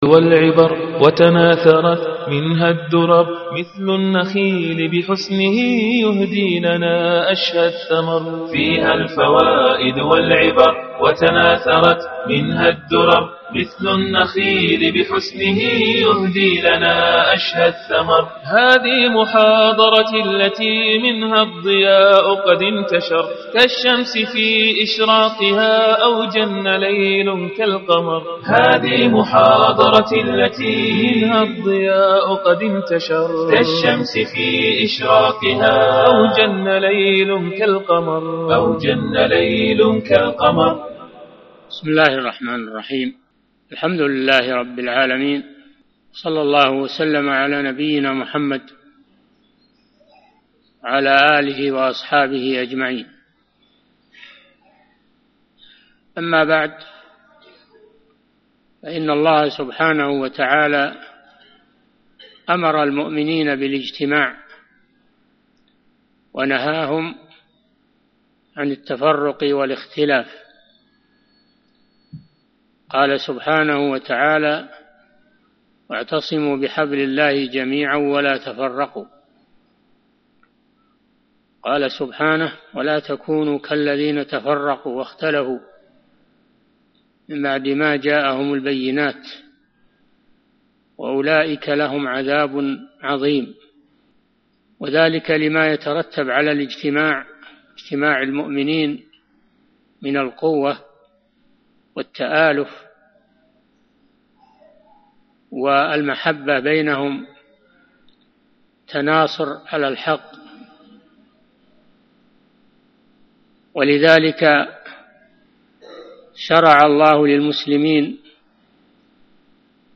Download audio file Downloaded: 263 Played: 1379 Artist: الشيخ صالح بن فوزان الفوزان Title: مفهوم البيعة وحكم الخروج على ولاة الأمر Length: 45:02 minutes (10.34 MB) Format: MP3 Mono 44kHz 32Kbps (CBR)